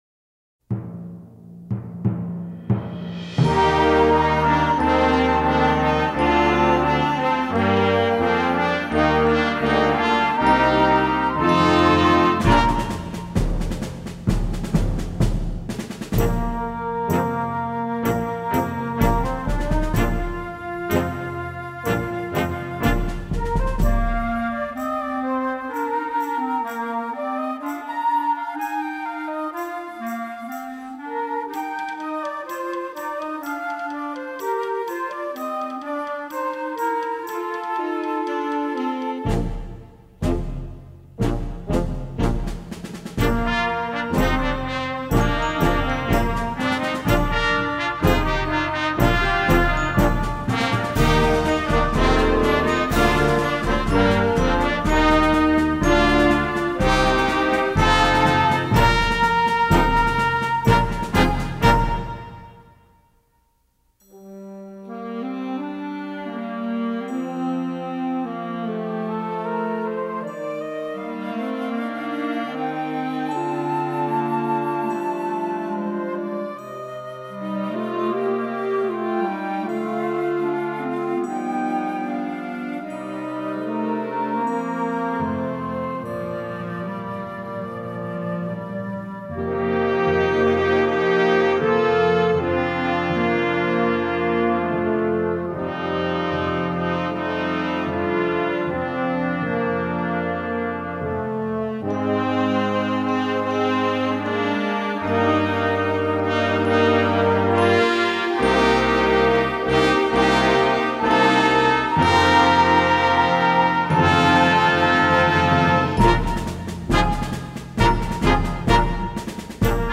Blasorchester PDF
Strong and energetic!
The band set also includes an optional keyboard.